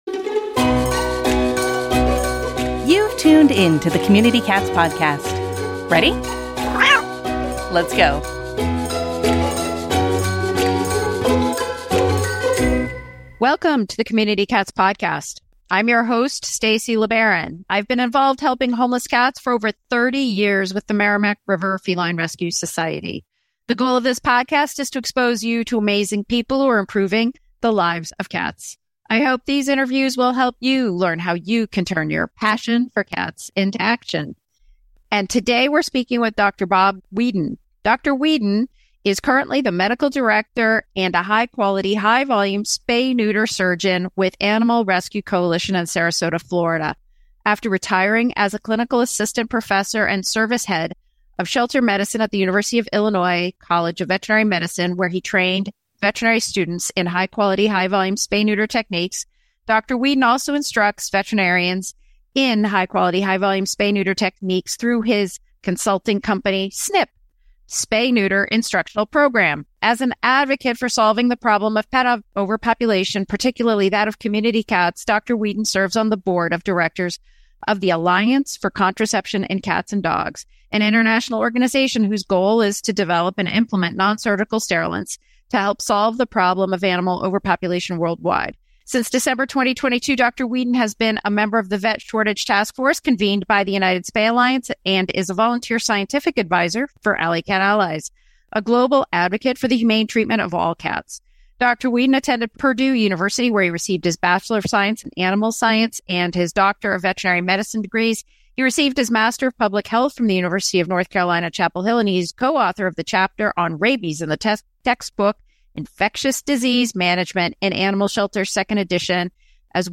A conversation about the science of rabies immunity, misconceptions about diseases like toxoplasmosis, and why cat advocates should focus on reducing misinformation.